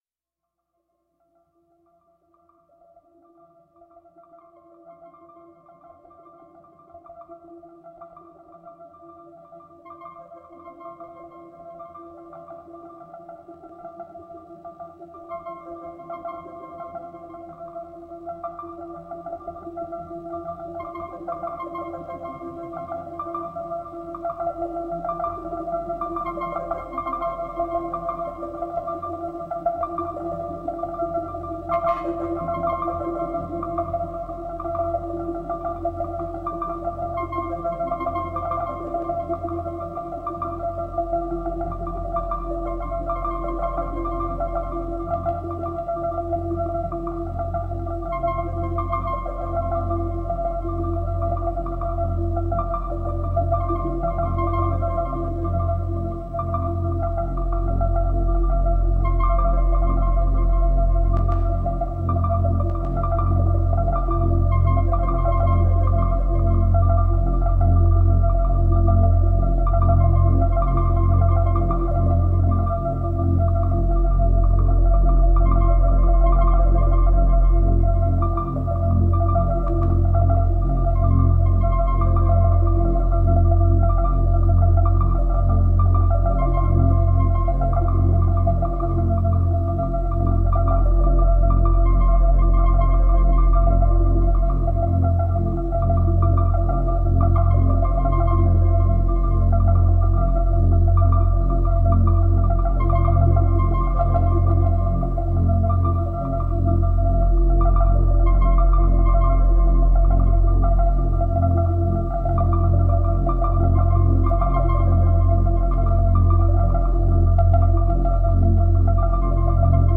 Deeply ambient new music.
Tagged as: Ambient, New Age, Ethereal, Space Music